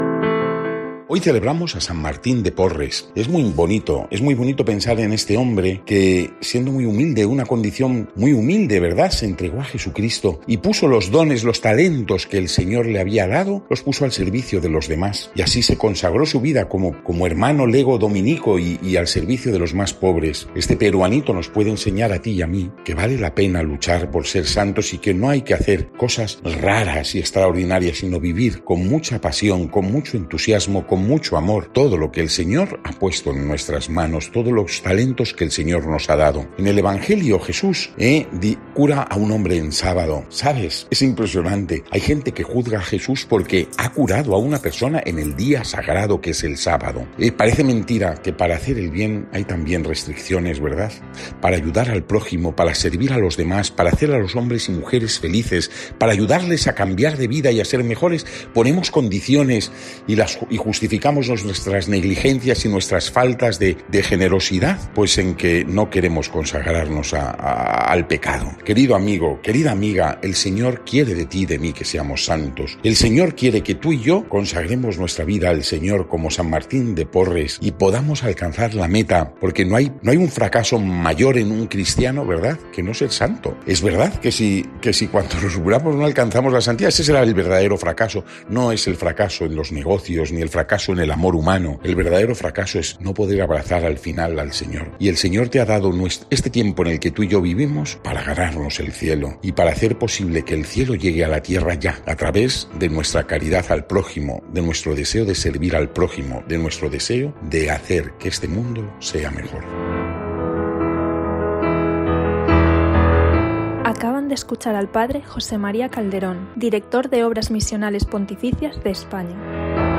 Lectura del santo evangelio según san Lucas 14,1-6